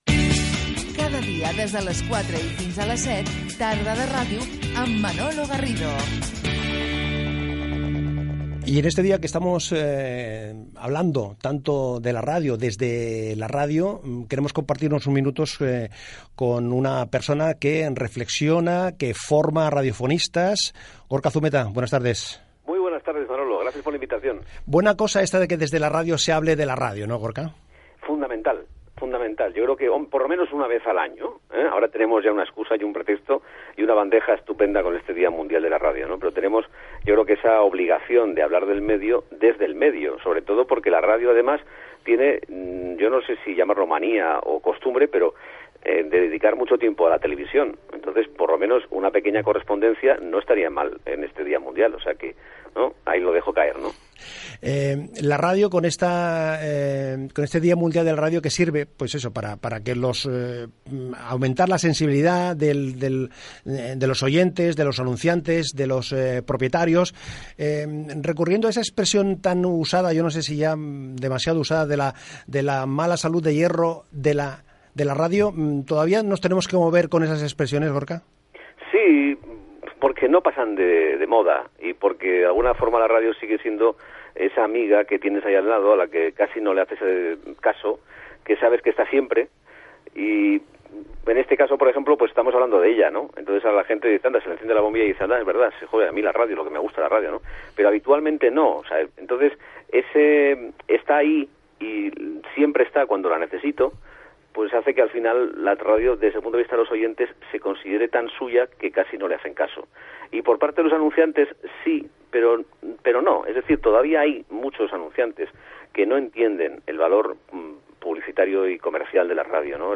Indicatiu del programa
Entreteniment